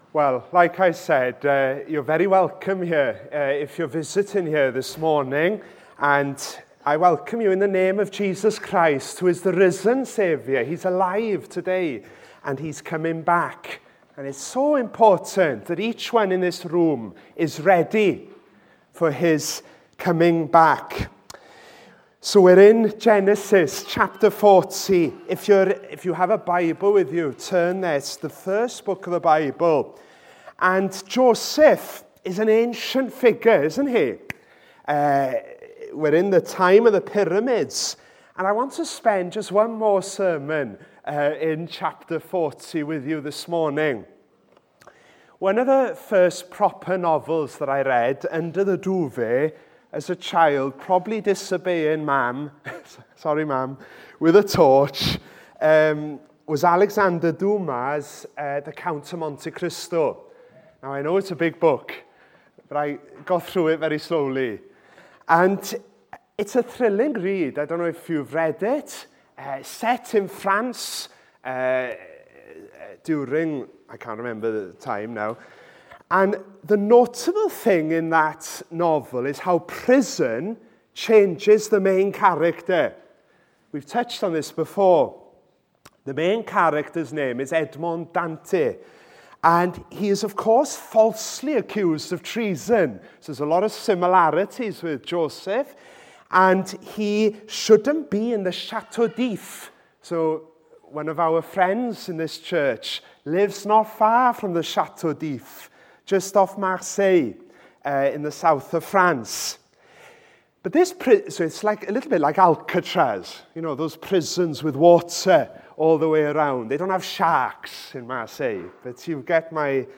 28 September 2025, Morning Sermon